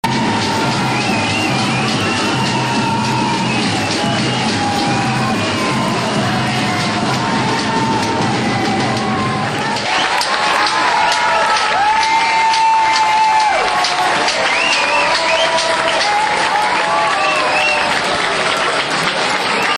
Al grito de 'campeones, campeones' y al son de una gran batucada.